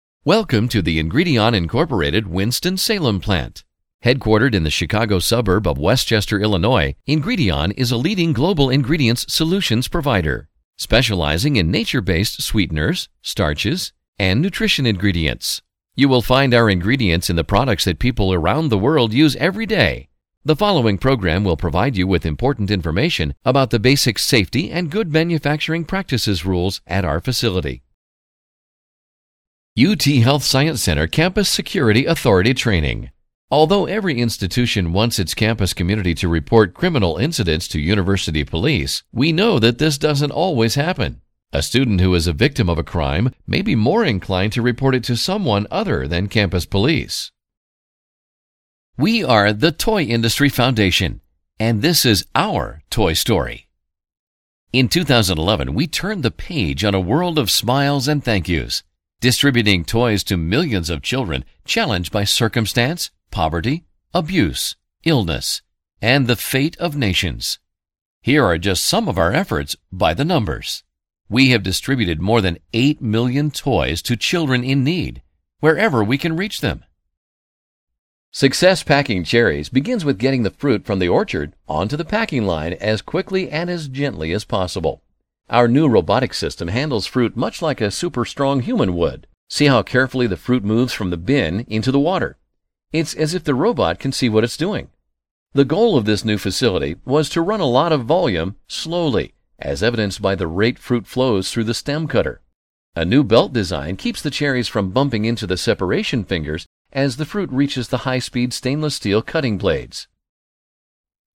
I can offer a warm, deep, personable voice over for narrations, training videos and presentations.
Sprechprobe: Sonstiges (Muttersprache):